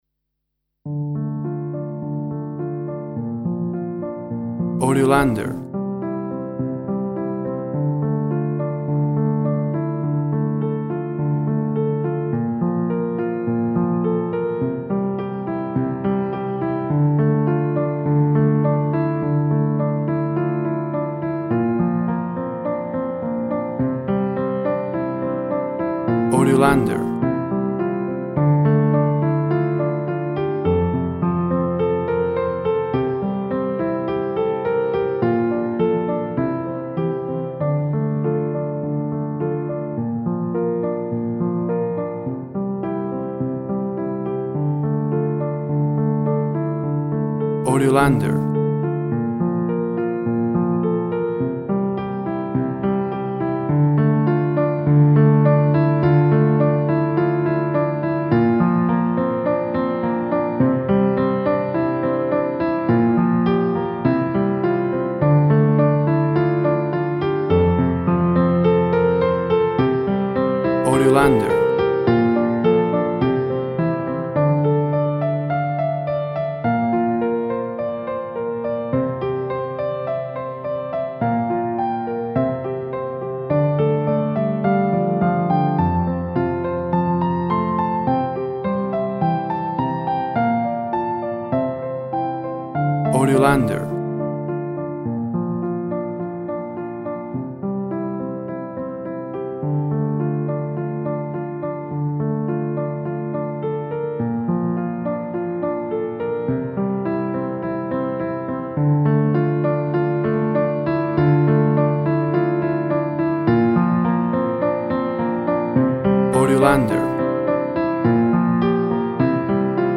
WAV Sample Rate 16-Bit Stereo, 44.1 kHz
Tempo (BPM) 105